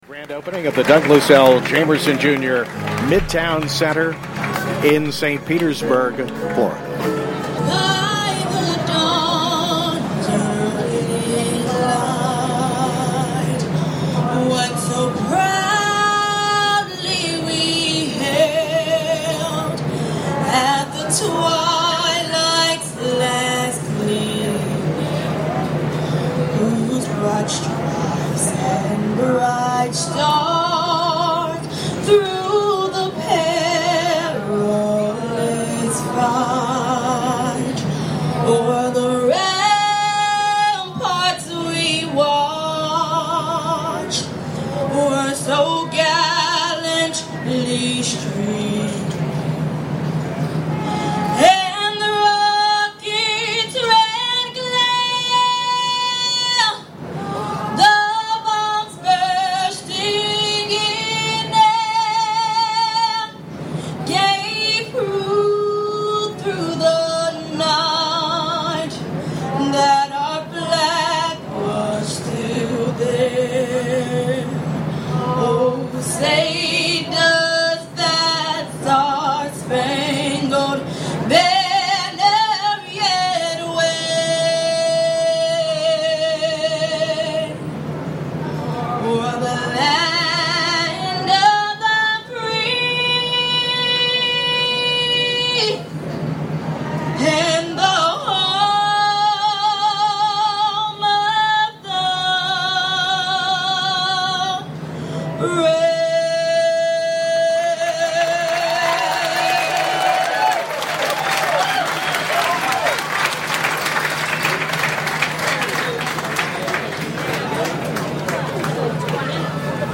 Douglas L. Jamerson, Jr. Midtown Center Dedication St. Petersburg College 8-1-15